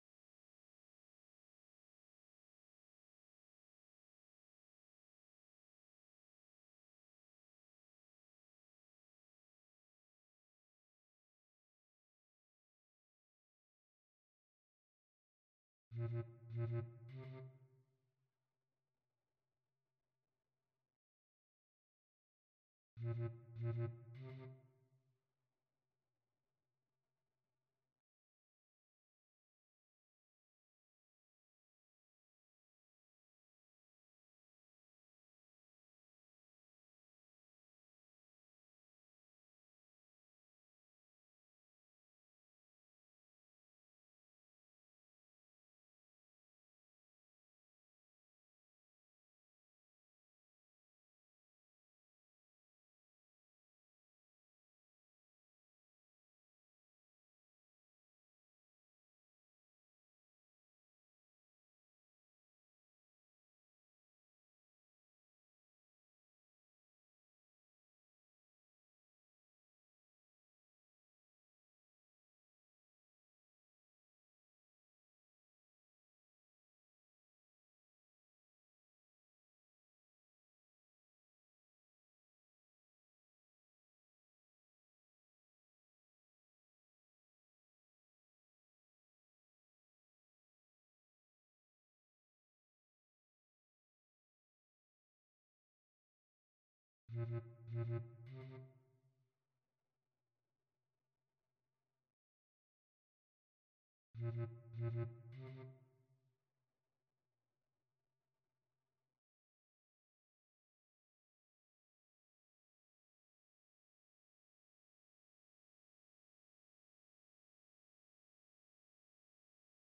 Voicing: Bb Clarinet Quartet